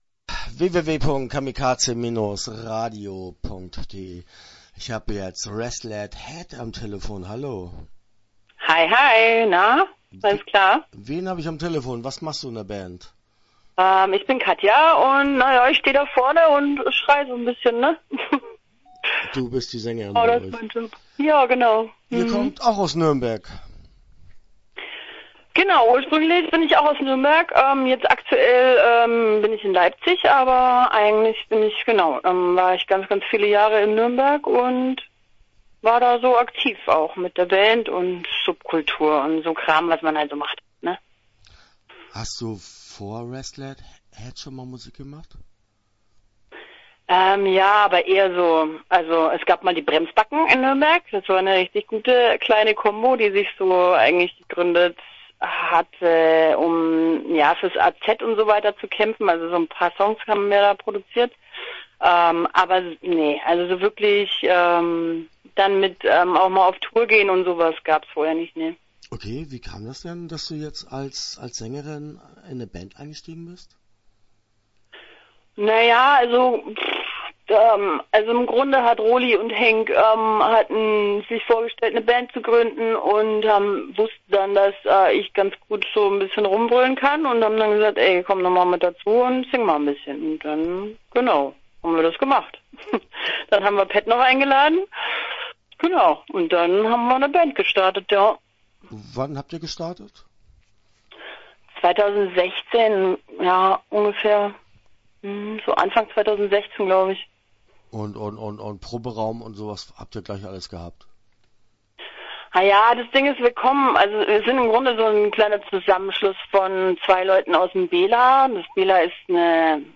Start » Interviews » Restless Head